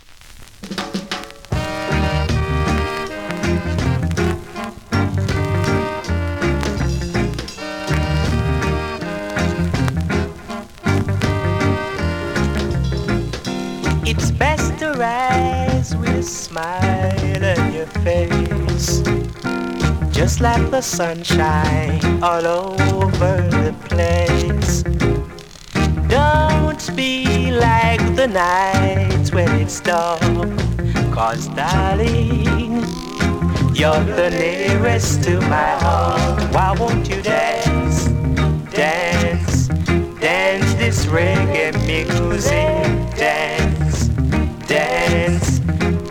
NEW IN!SKA〜REGGAE
スリキズ、ノイズ比較的少なめで